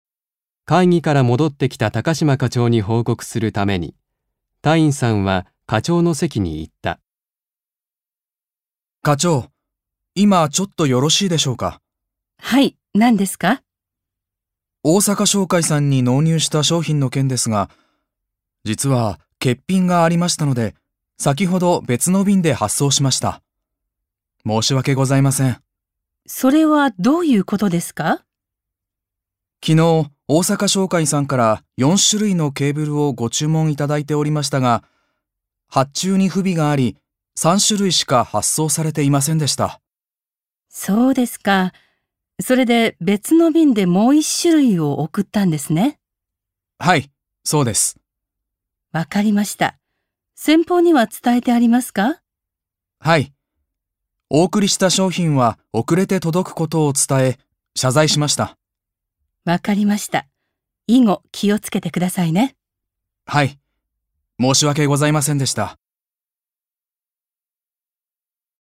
1. 会話（仕事のミスを上司に報告する）